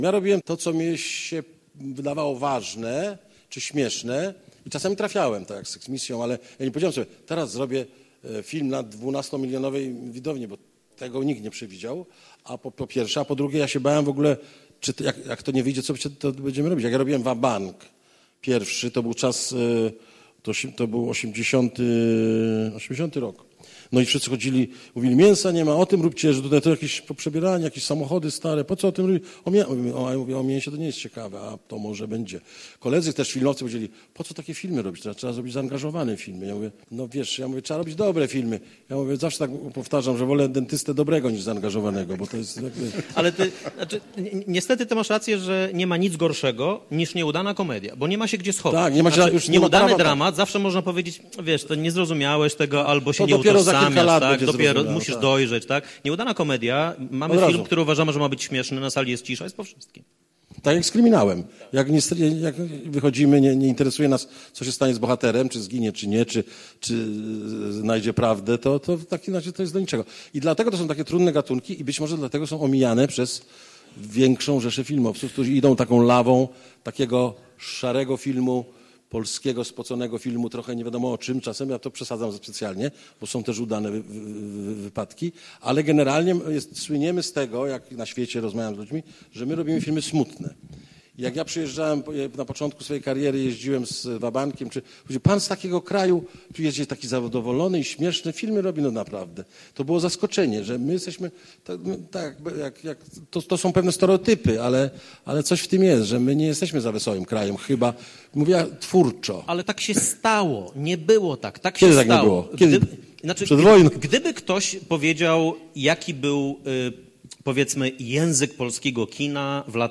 Juliusz Machulski i Michał Chaciński byli niedawno w Teatrze Starym w Lublinie gośćmi Jerzego Sosnowskiego. Rozmowa dotyczyła polskich filmów komediowych. Juliusz Machulski, reżyser, producent, scenarzysta, dramaturg i Michał Chaciński, krytyk filmowy, producent i dziennikarz, różnili się nieco w opiniach na ten temat.